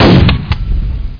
expfire1.mp3